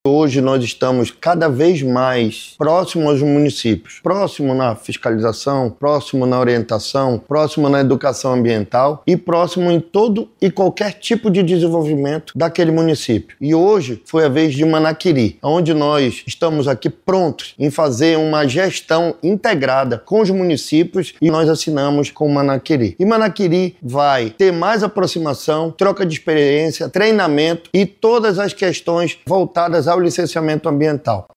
O diretor-presidente do Instituto, Gustavo Picanço, destaca a importância da aproximação do Ipaam com os municípios do interior do Estado para a implementação de políticas ambientais.